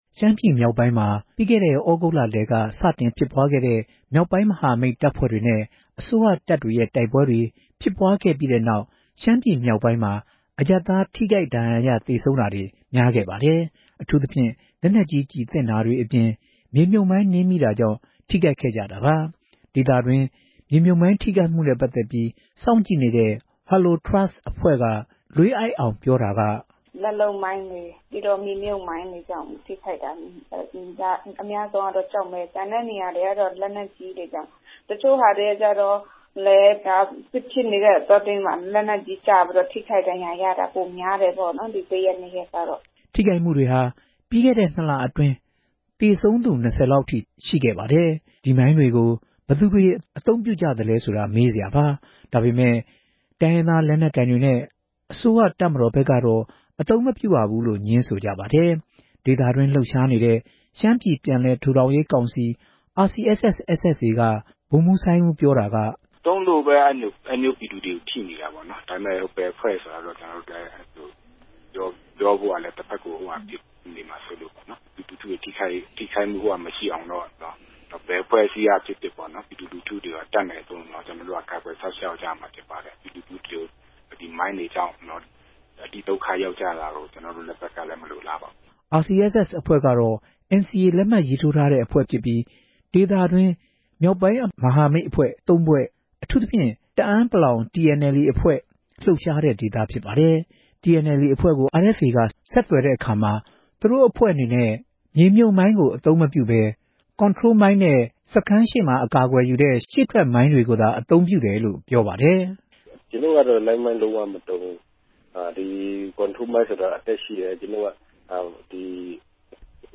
ဆက်သွယ်မေးမြန်းပြီး တင်ပြထားပါတယ်။